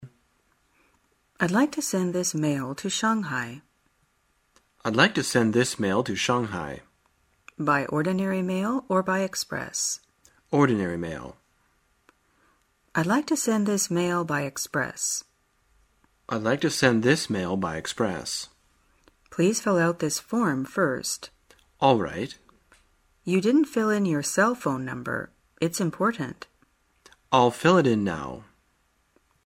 真人发音配字幕帮助英语爱好者们练习听力并进行口语跟读。